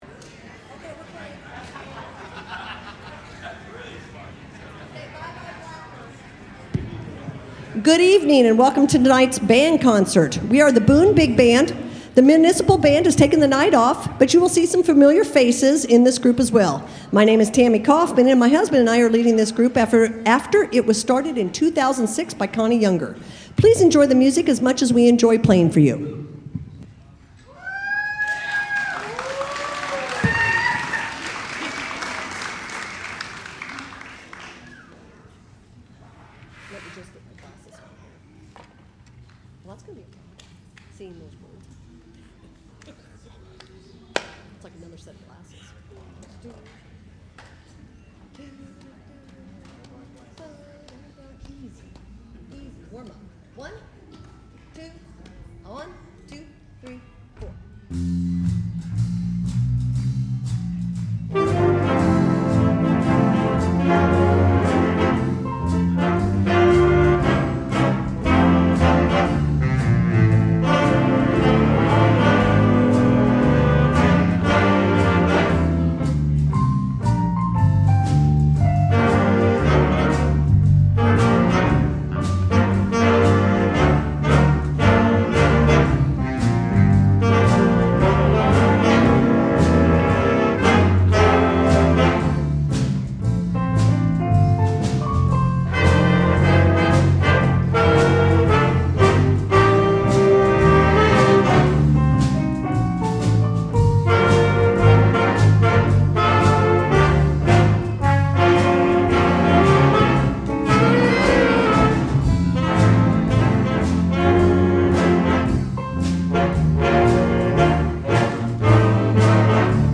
The Boone Municipal Band regular Wednesday Concert on June 25, 2025 featured the Boone Big Band and their annual concert. This aired on KWBG, Sunday, June 29, 2025.